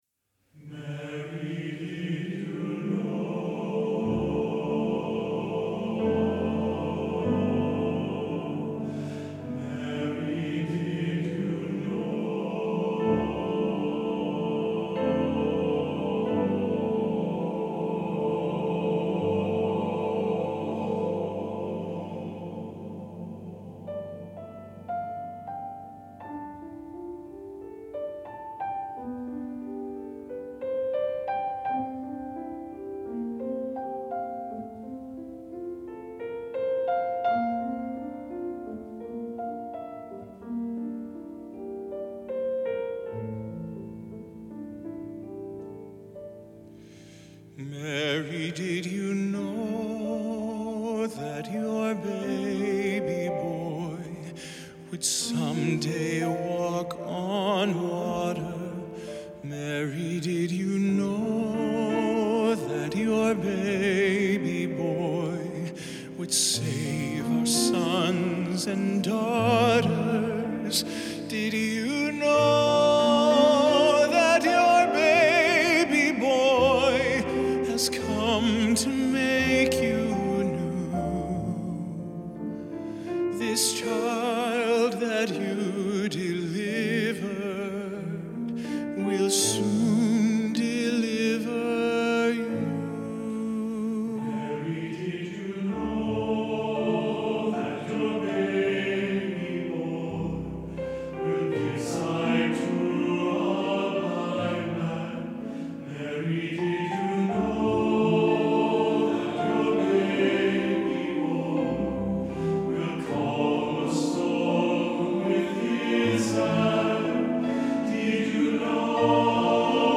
Voicing: TTBB and Piano